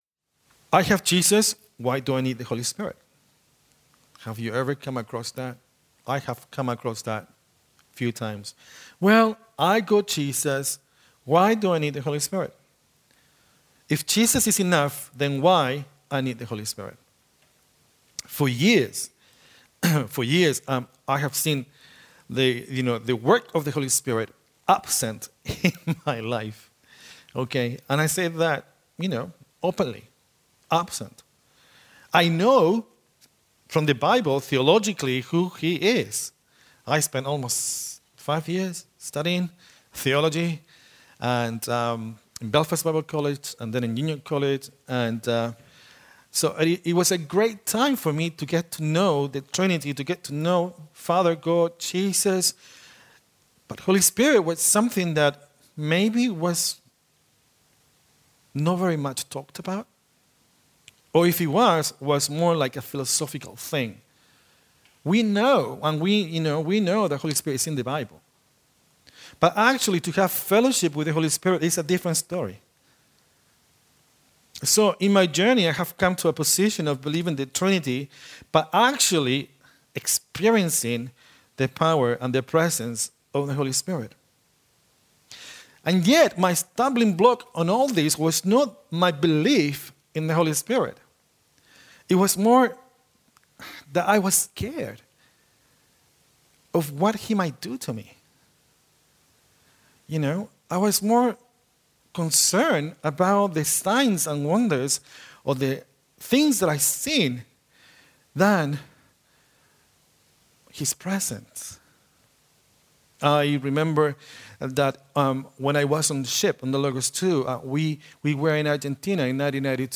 Sunday 25th March Evening Service